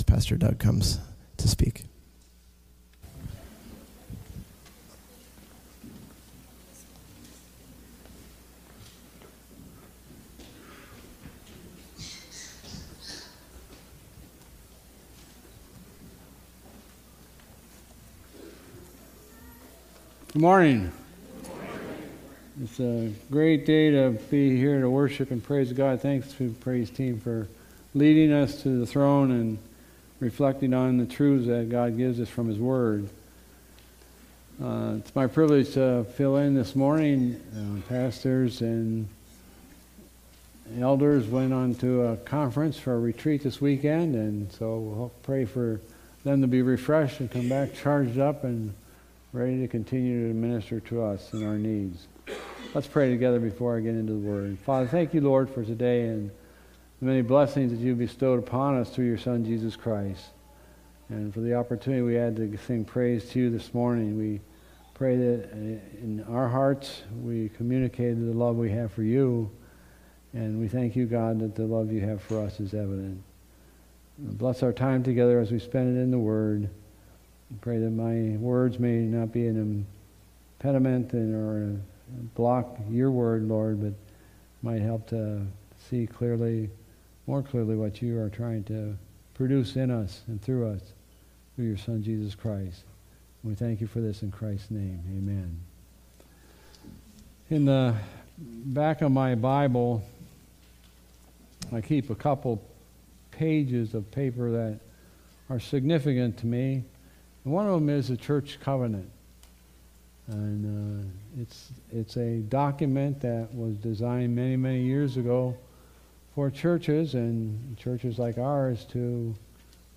Sermons | Calvary Baptist Church
Single sermons that are not part of a series.